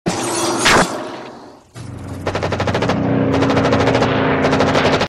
Military Free sound effects and audio clips
• MACHINE GUNS FIRING AND AIRPLANE.mp3
machine_guns_firing_and_airplane_xvl.wav